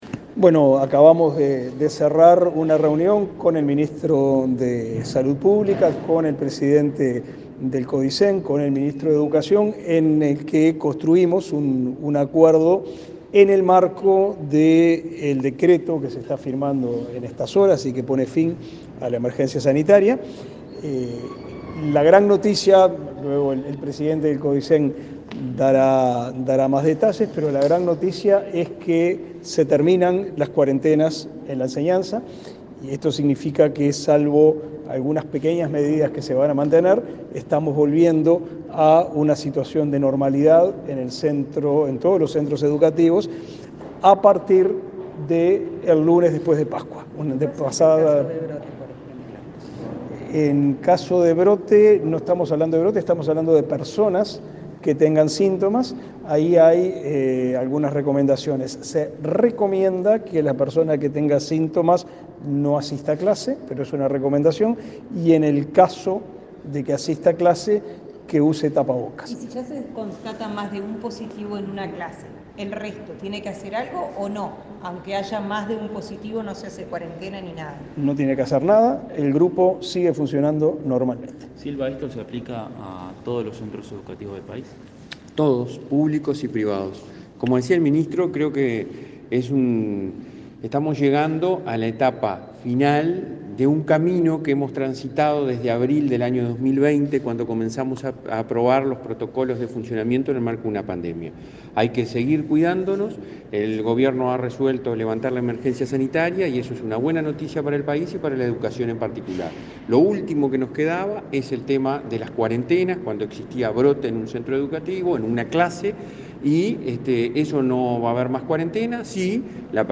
Declaraciones de prensa de autoridades educativas
Declaraciones de prensa de autoridades educativas 05/04/2022 Compartir Facebook X Copiar enlace WhatsApp LinkedIn Este martes 5, el ministro de Educación y Cultura, Pablo da Silveira, y el presidente de la ANEP, Robert Silva, dialogaron con la prensa, luego de reunirse con el ministro de Salud Pública, Daniel Salinas, para hablar del protocolo sanitario en centros educativos.